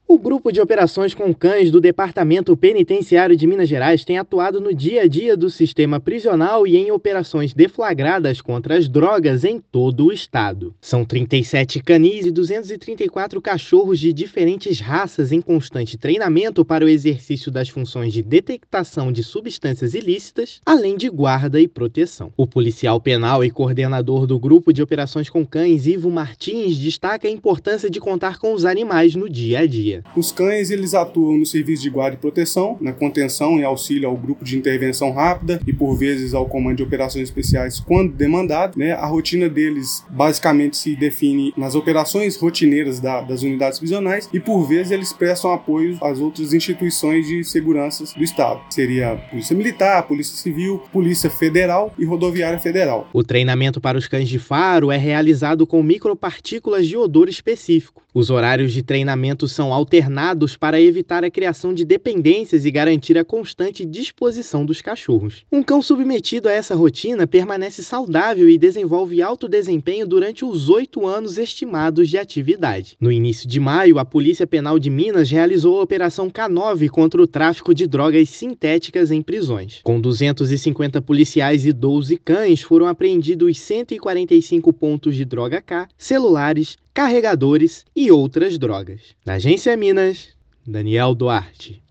[RÁDIO] Cães farejadores da Polícia Penal aumentam a eficácia de operações contra as drogas em Minas
Animais também realizam função de guarda e manutenção da ordem em unidades prisionais do Estado. Ouça a matéria de rádio: